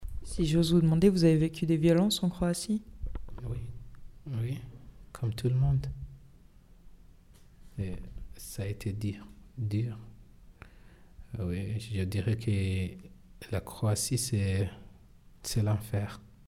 AUDIO: Réfugié burundais: “la Croatie, c’est l’enfer”
Refugie-burundais_01.mp3